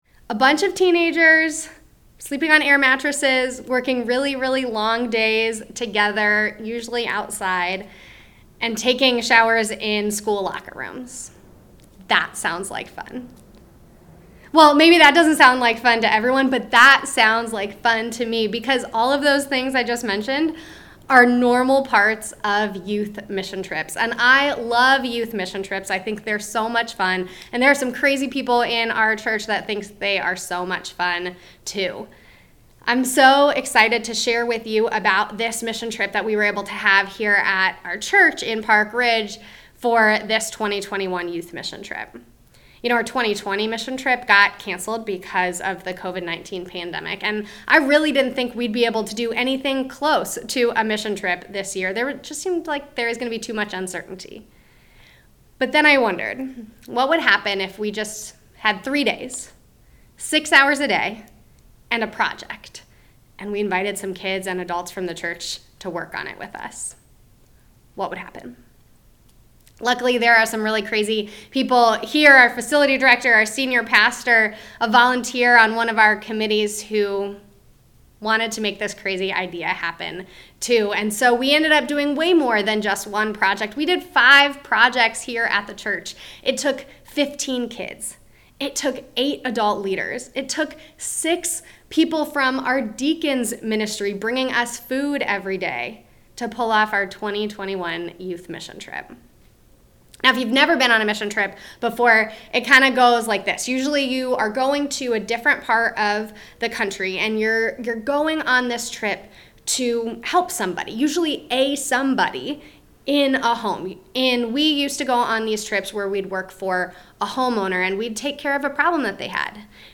Sermon-July-18th.mp3